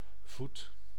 Ääntäminen
Synonyymit poot Ääntäminen : IPA: /vut/ Haettu sana löytyi näillä lähdekielillä: hollanti Käännös Konteksti Ääninäyte Substantiivit 1. foot anatomia US UK 2.